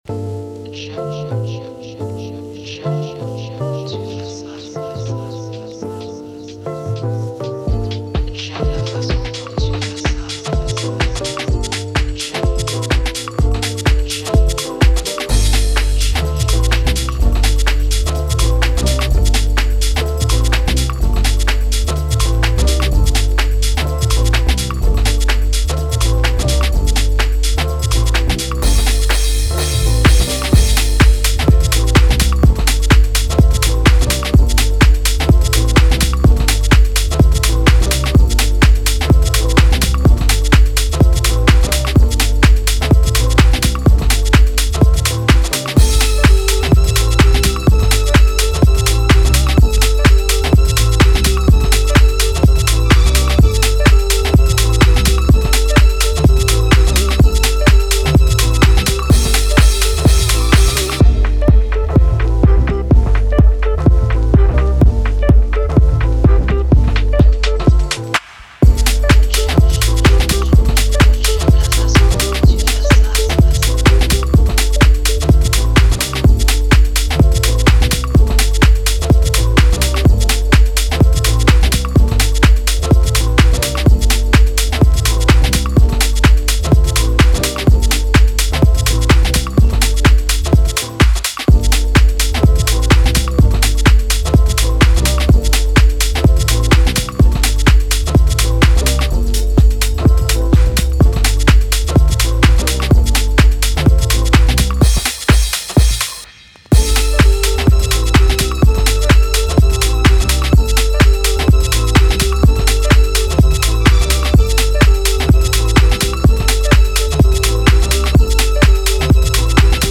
2025 in Official Instrumentals , R&B Instrumentals